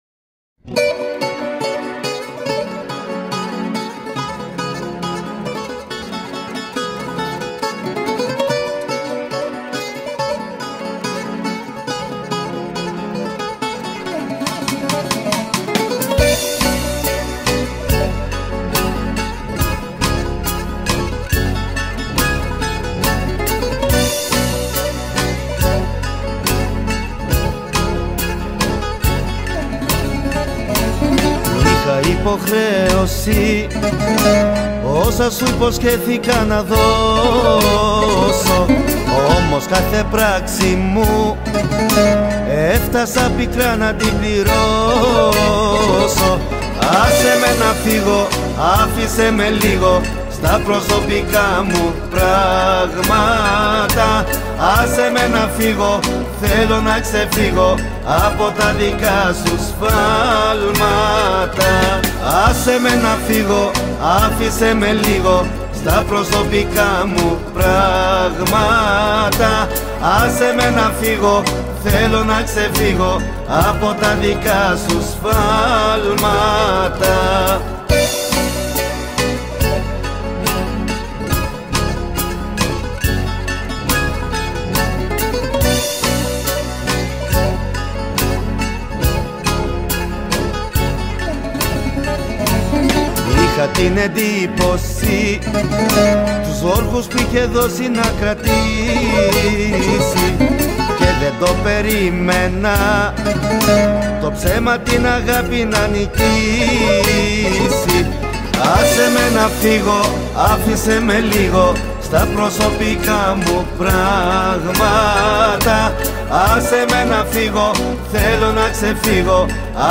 Жанр: ΠΟΠ και ΛΑΪΚΆ